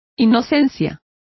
Complete with pronunciation of the translation of innocence.